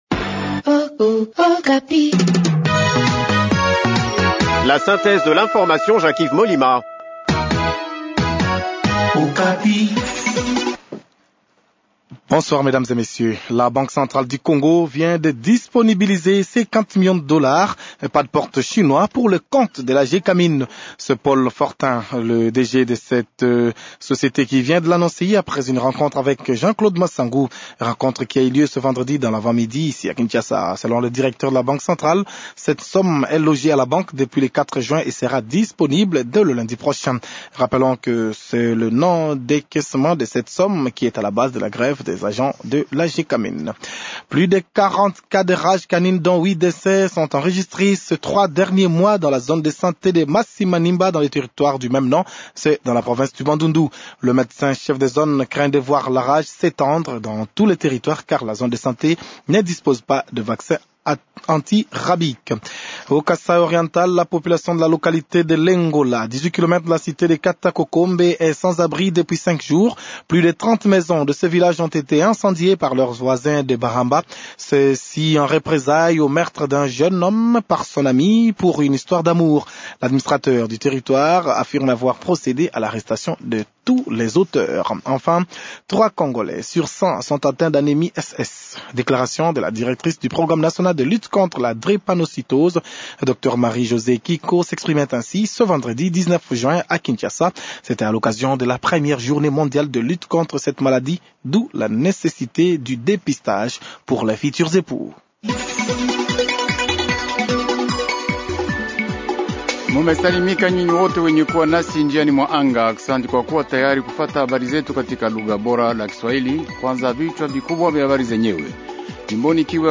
Journal Swahili Soir | Radio Okapi